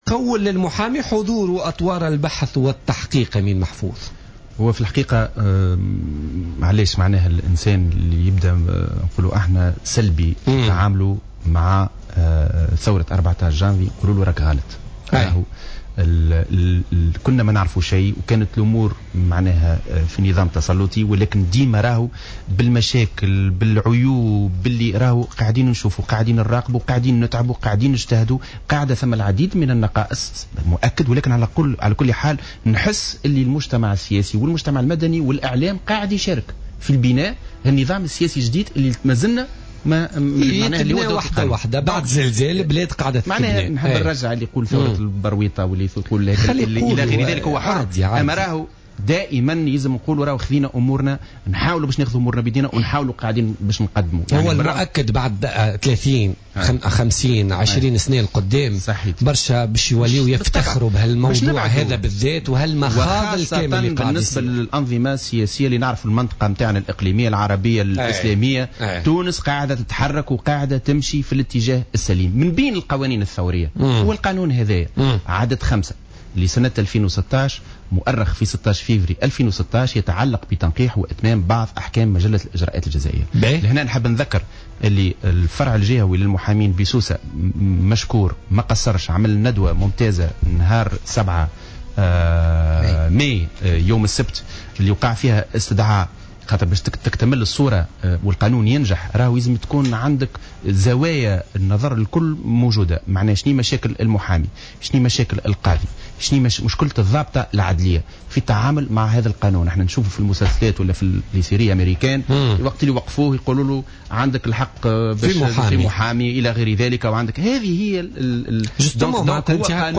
وأضاف في مداخلة له في برنامج "بوليتيكا" أن القانون يمنع حصول تجاوزات ويساعد على مراقبة سلامة الإجراءات، مشيرا إلى أن هذا القانون الذي سيدخل حيز النفاذ في غرة جوان 2016 سيثير بعض المشاكل وأنه يجب تداركها بمشاركة جميع الأطراف المتداخلة.